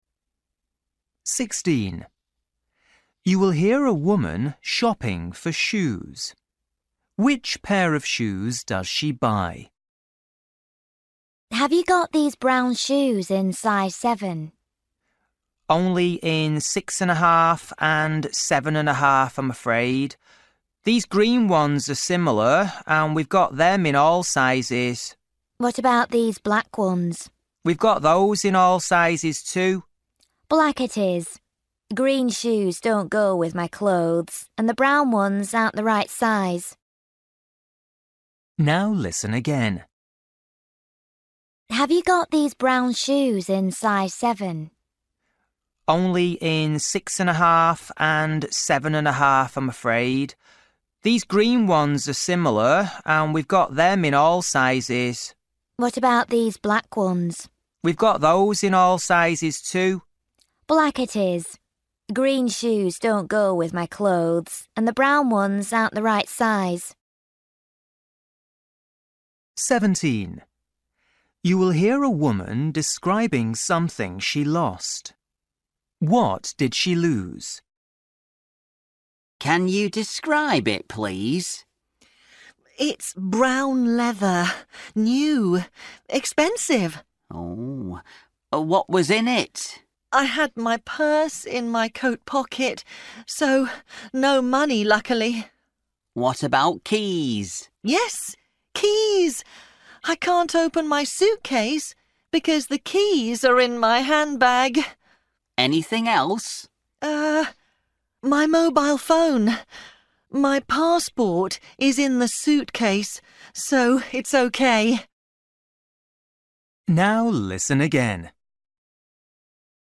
Listening: everyday short conversations
16   You will hear a woman shopping for shoes.
18   You will hear two friends talking.
19   You will hear a man talking about his holiday to his friend.
20   You will hear a woman talking to her friend.